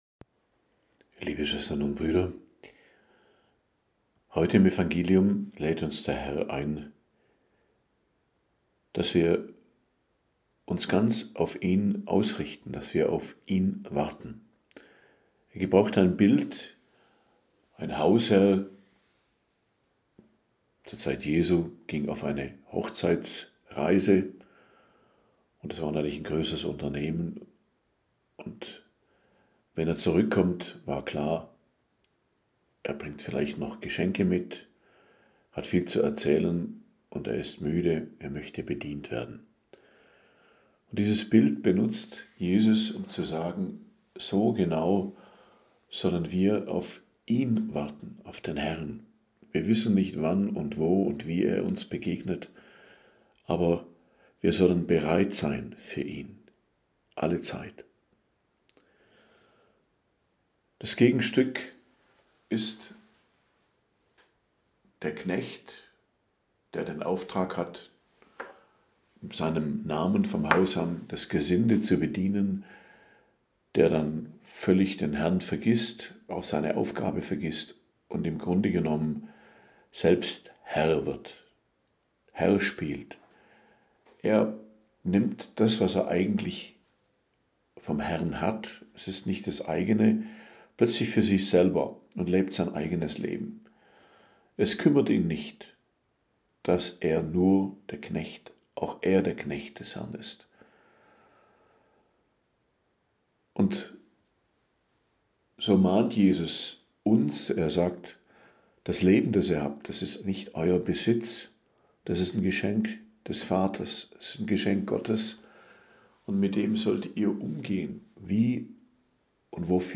Predigt zum 19. Sonntag i.J., 10.08.2025